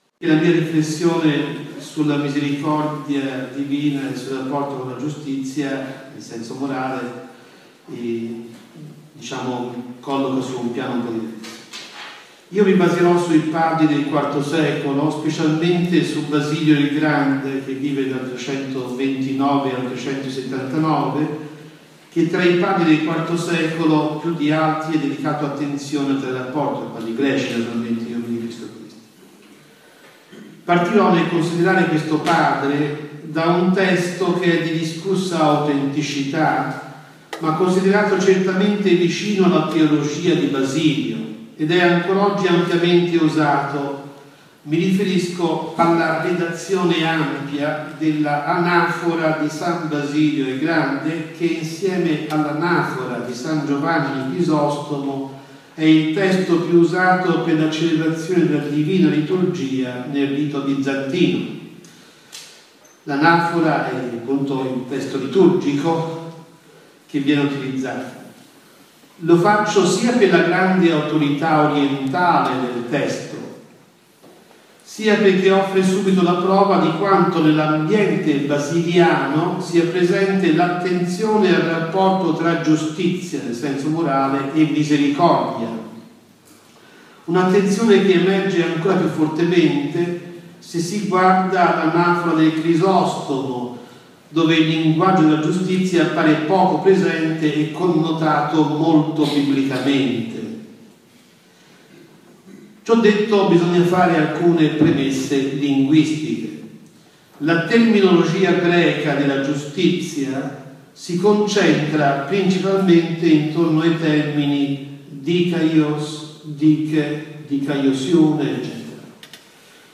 Lʼesposizione in classe
Registrazione audio della lezione Il programma per lʼesame Da concordare con il Docente...